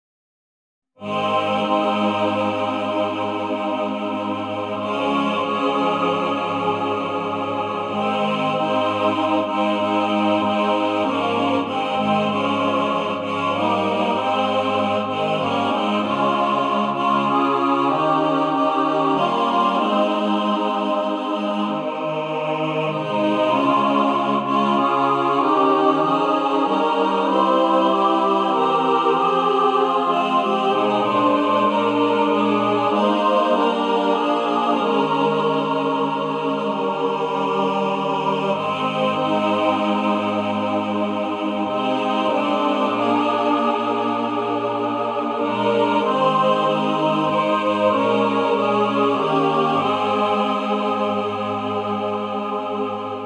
A bold, sacred, patriotic hymn